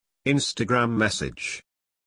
Звуки Инстаграм уведомлений
На этой странице собраны все популярные звуки уведомлений и сообщений из Instagram.